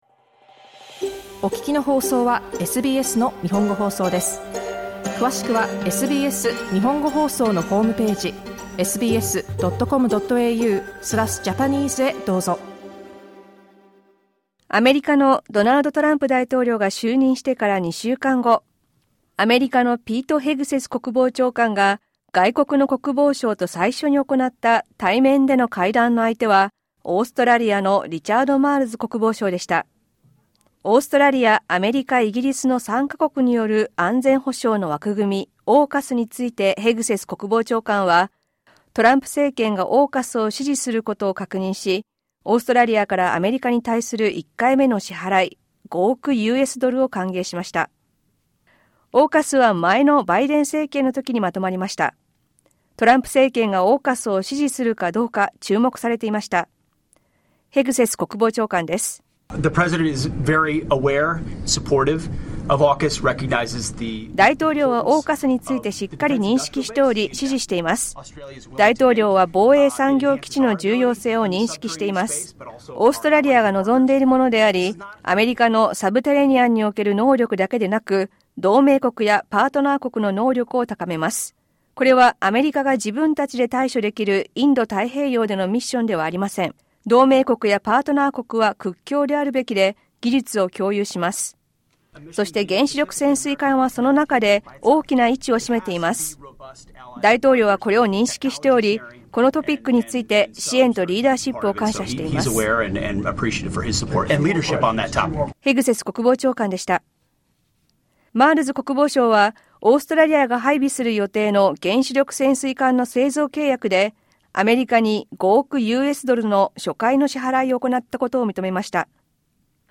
詳しくは音声リポートからどうぞ。